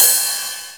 TR 909 Free sound effects and audio clips
• Ride Sample B Key 07.wav
Royality free ride cymbal sound tuned to the B note. Loudest frequency: 8811Hz
ride-sample-b-key-07-0H4.wav